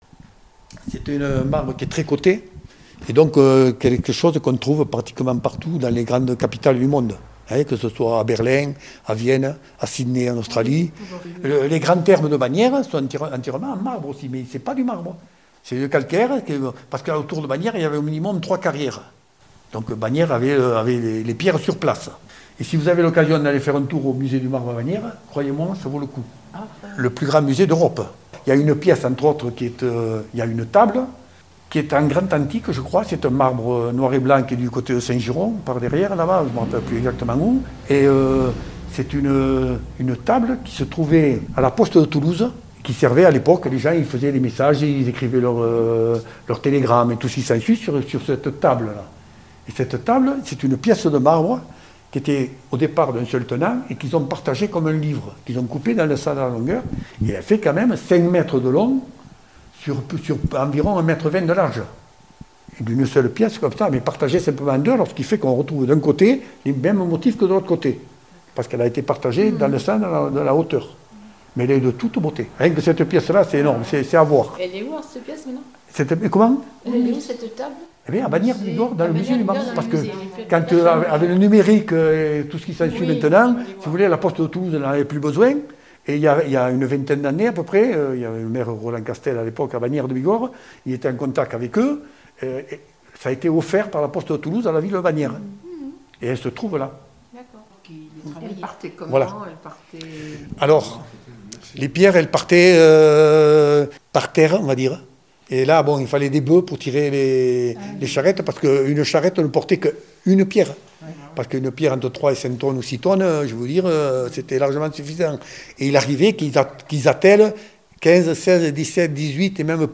vendredi 1er août 2025 Interview et reportage Durée 10 min